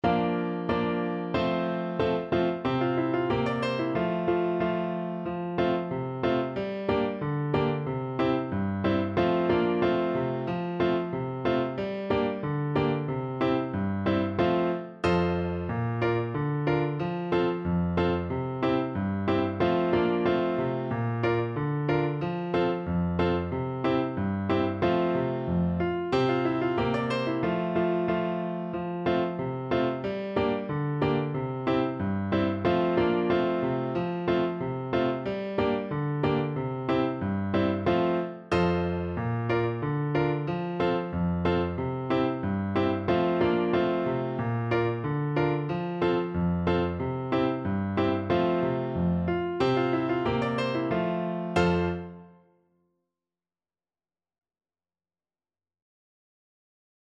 2/2 (View more 2/2 Music)
Jolly =c.92